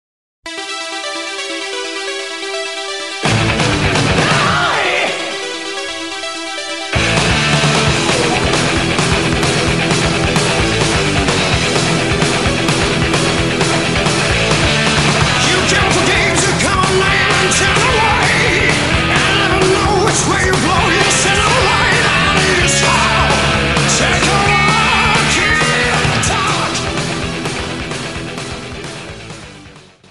キャッチーさとパワーに撃沈される。